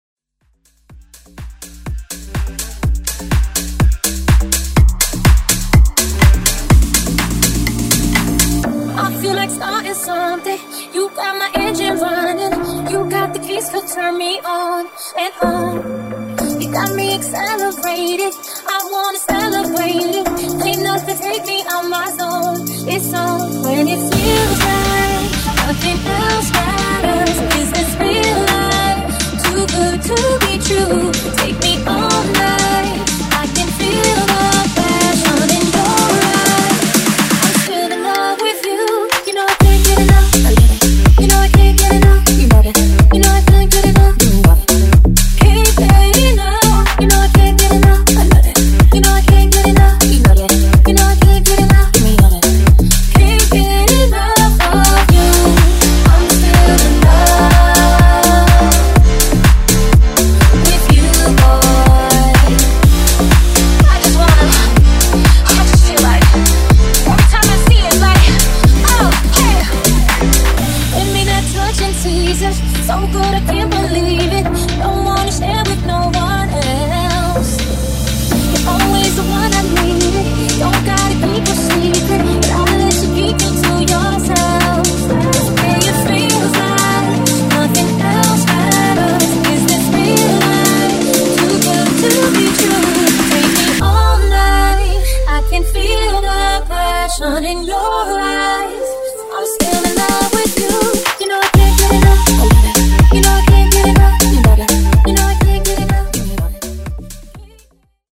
Genre: OLD SCHOOL HIPHOP
Clean BPM: 103 Time